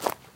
step1.wav